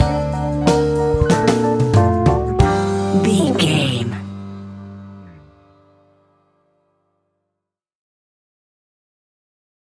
Fast paced
In-crescendo
Uplifting
Ionian/Major
hip hop